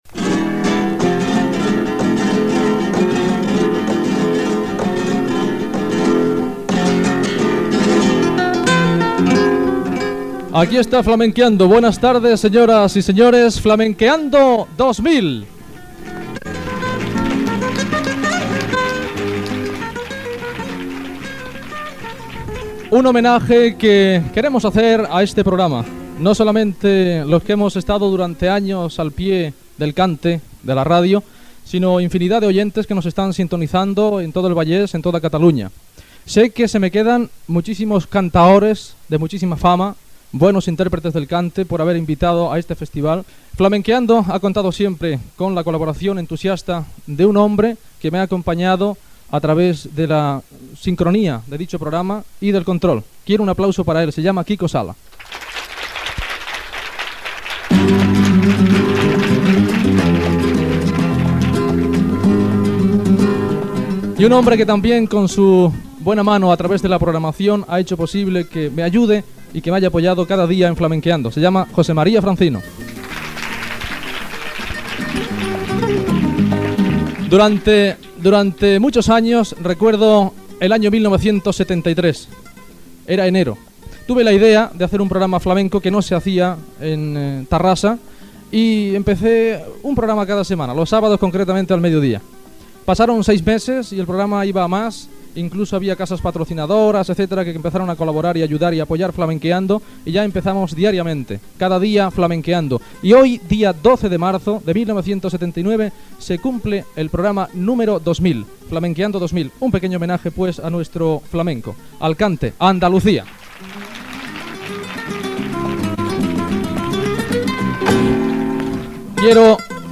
Musical
El programa es va enregistrar a la sala d'actes de l'emissora.